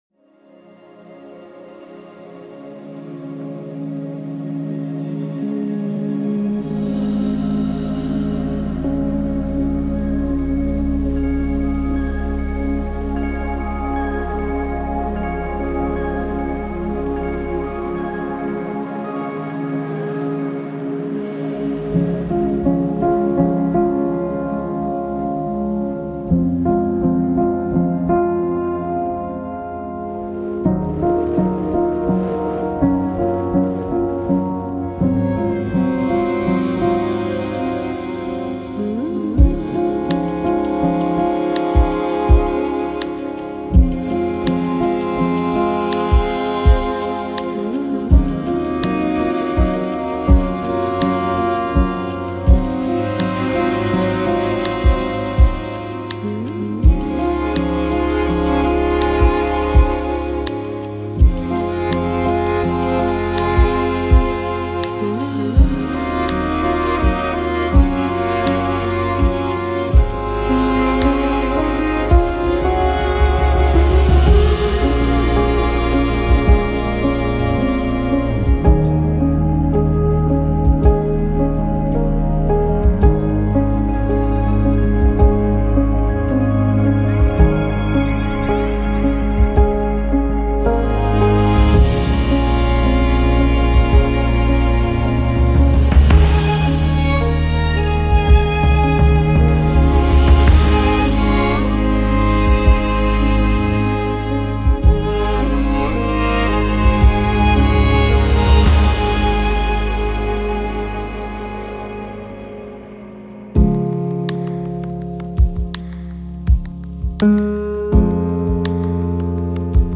موزیک اینسترومنتال